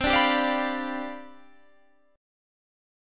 CM7sus2sus4 chord